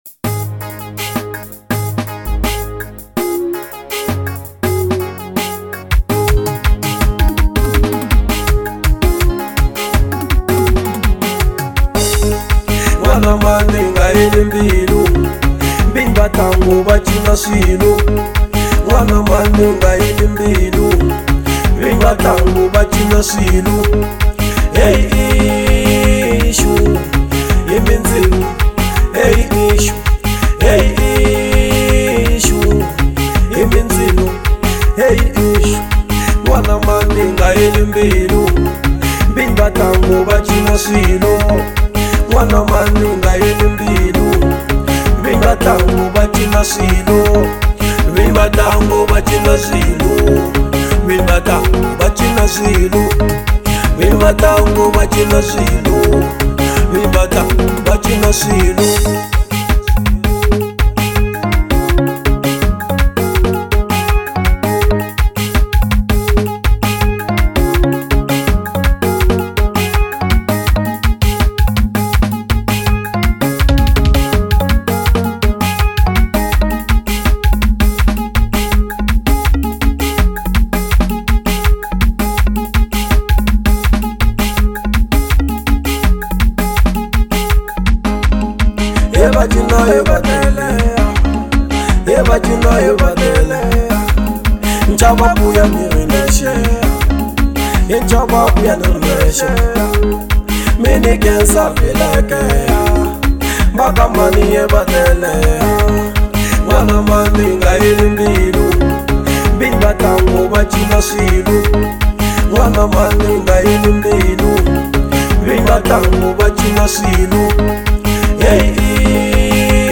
03:40 Genre : Xitsonga Size